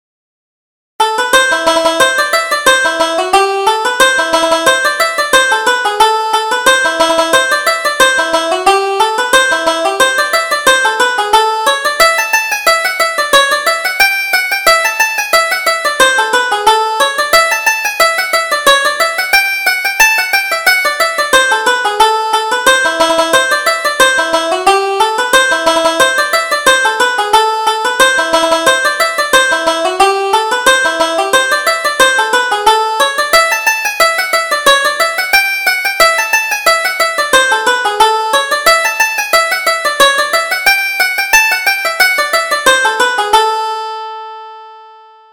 Reel: Scotch Mary